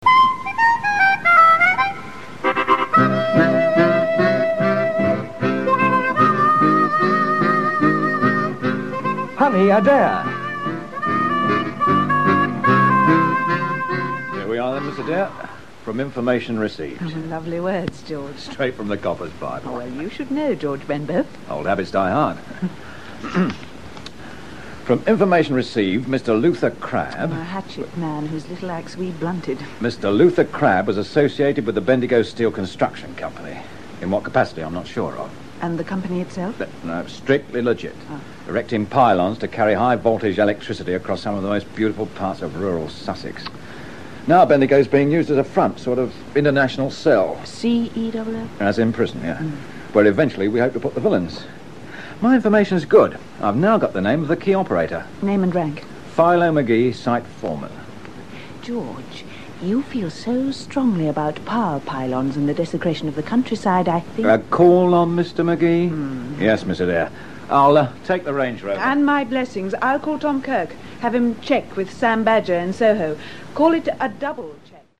Hence even Capital, in its early days, carried short bursts of drama between programmes, like 'The King and His Mistresses' at the outset; and later, as you can hear here 'Honey Adair'